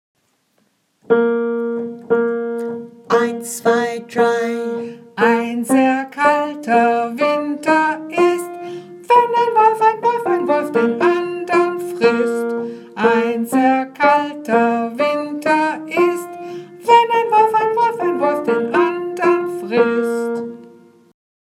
A new German round